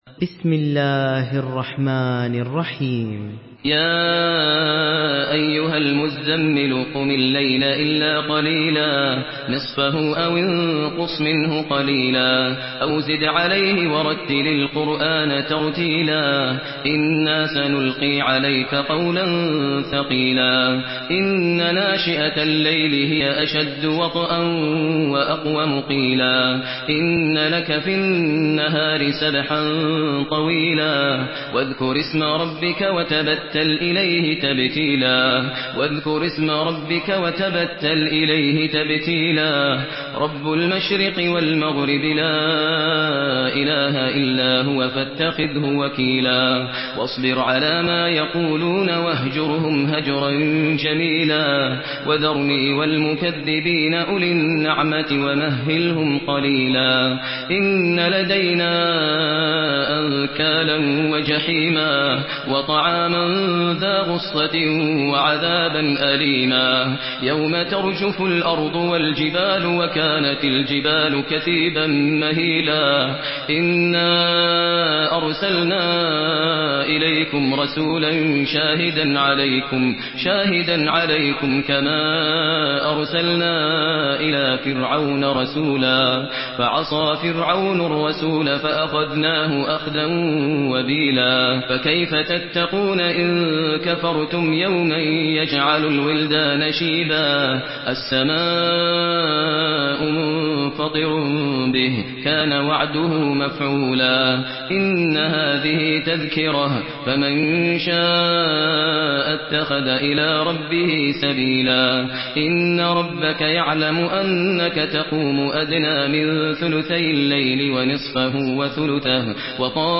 Surah Müzemmil MP3 by Maher Al Muaiqly in Hafs An Asim narration.